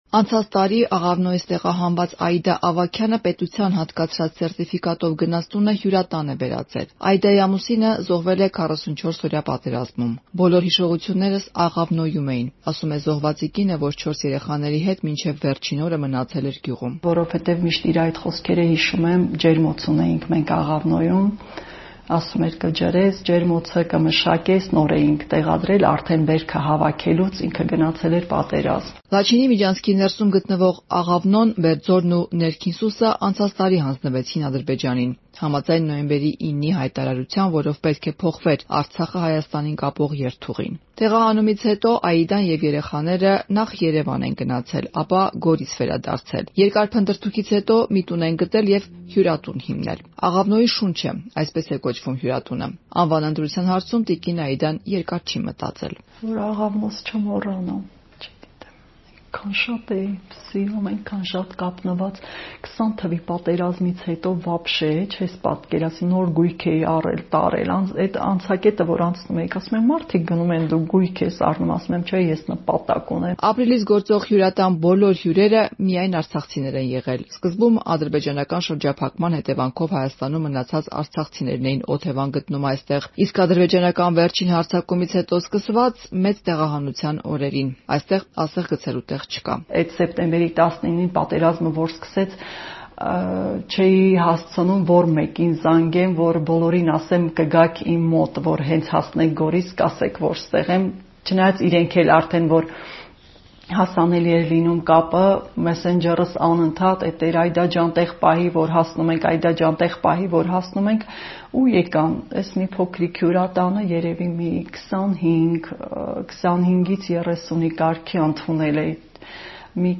Մանրամասները՝ «Ազատության» ռեպորտաժում.